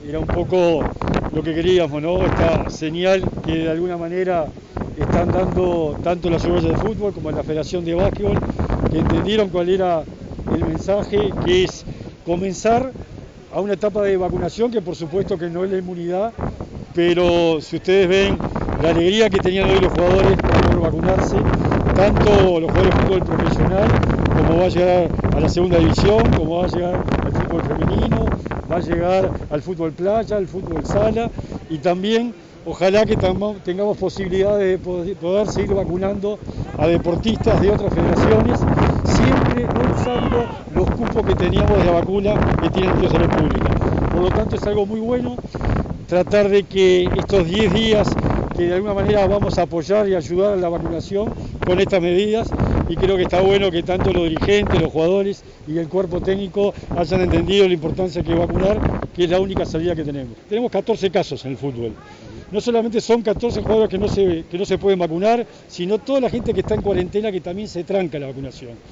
El secretario realizó estas declaraciones en la entrega del pabellón nacional a los remeros Bruno Cetraro y Felipe Klüver, para su participación en los Juegos Olímpicos.